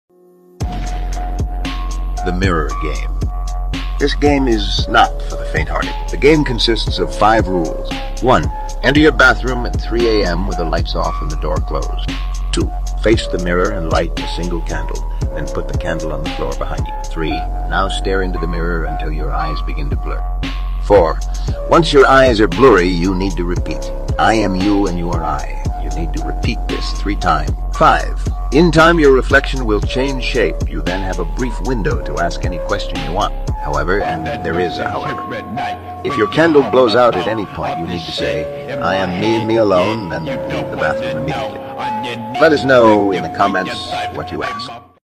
The mirror game#creepy#scary#scary background music#horror